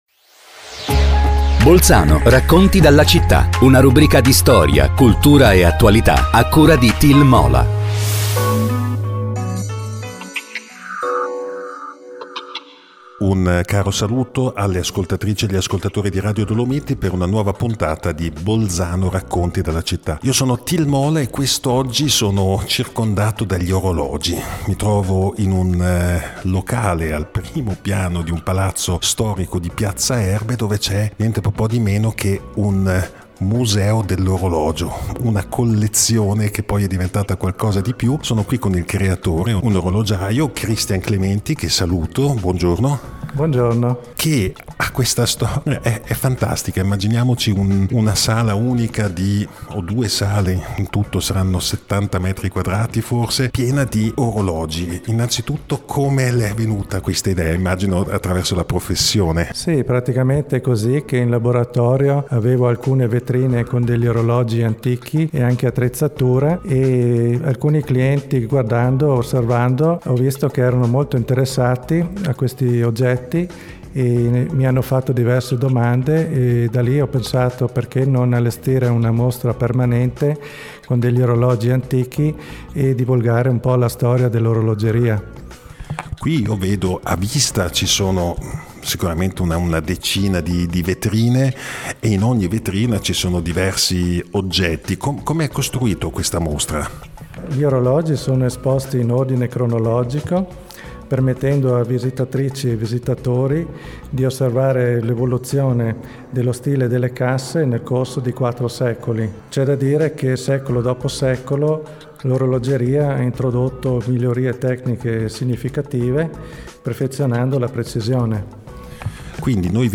Puntata del 6 marzo 2026 – Il museo dell’orologeria di Piazza Erbe. Intervista